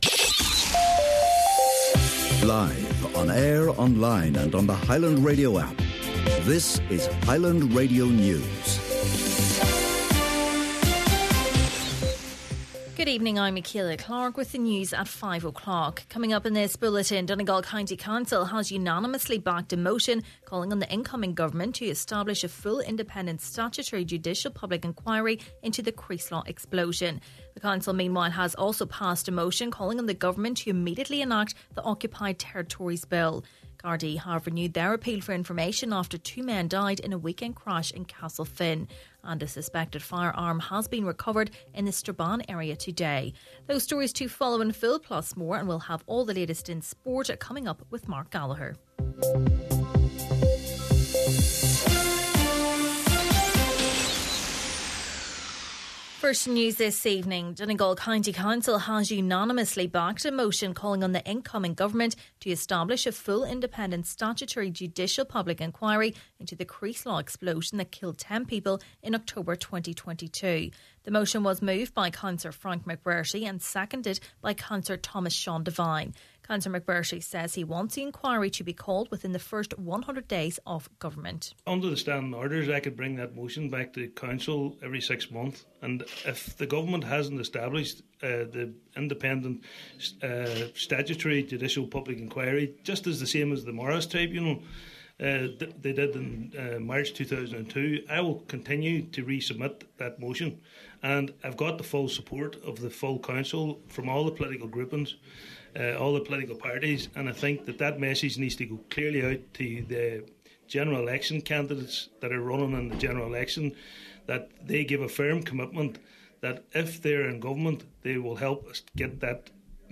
Main Evening News, Sport and Obituaries – Monday, November 25th